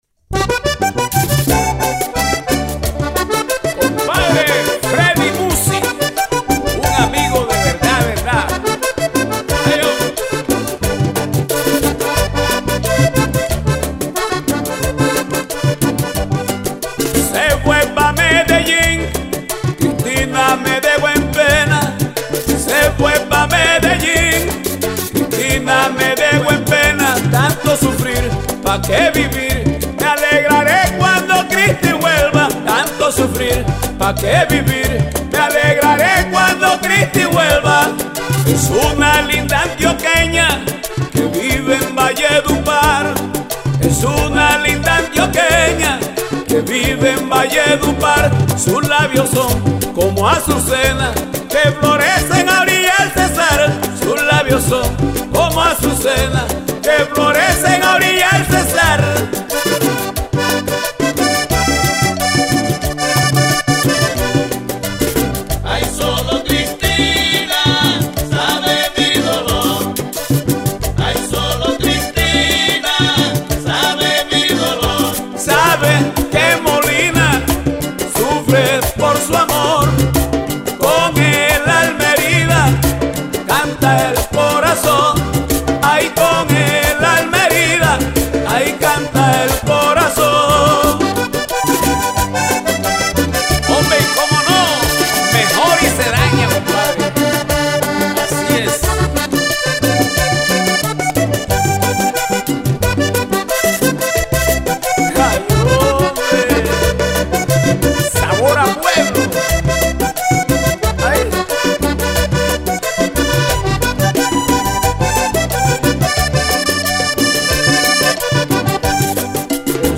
el recio vocalista cartagenero